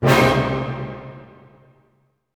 HIT ORCHM01R.wav